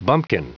Prononciation du mot bumpkin en anglais (fichier audio)
Prononciation du mot : bumpkin